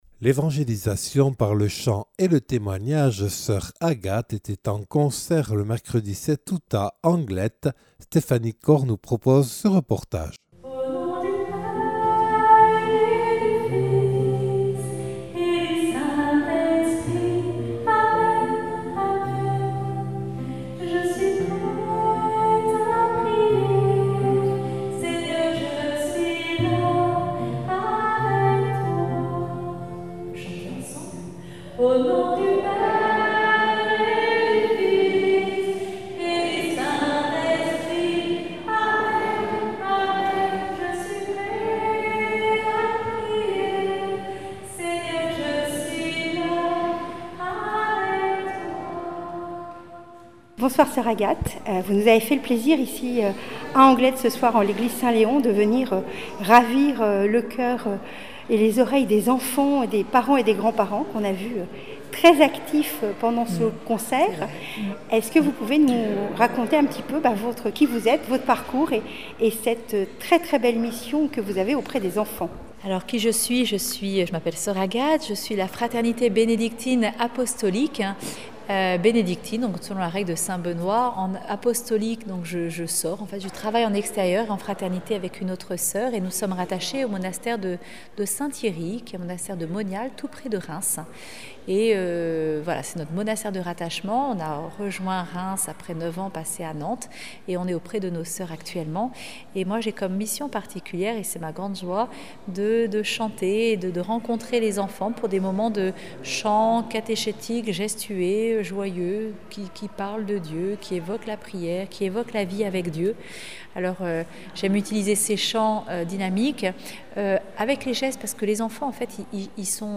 Reportage réalisé en l’église Saint-Léon d’Anglet le 7 août 2024.